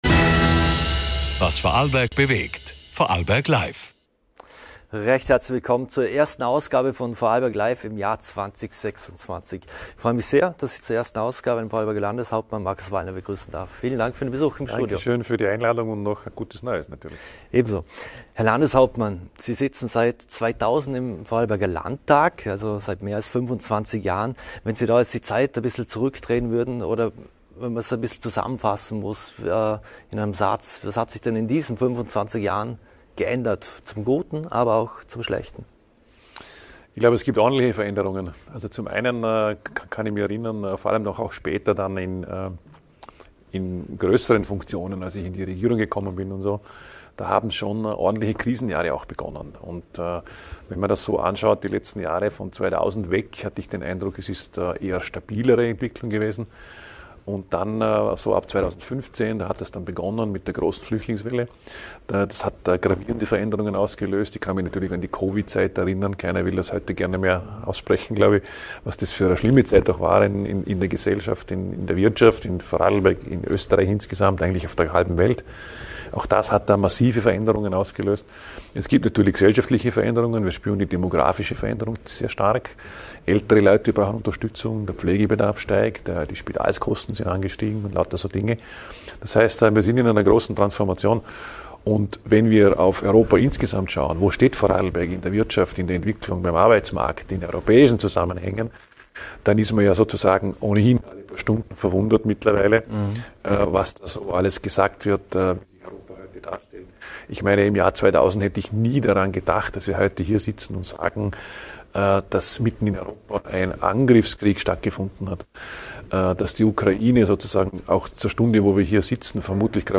Beschreibung vor 3 Monaten Wie gelingt Vorarlberg der Sprung aus dem Krisenmodus zurück zu Gestaltungskraft? In der ersten Vorarlberg LIVE‑Folge 2026 spricht Landeshauptmann Markus Wallner mit Moderator über die großen Herausforderungen und Perspektiven für das Land: Von wirtschaftlicher Stabilität über Bildung und Digitalisierung bis hin zur Generationengerechtigkeit.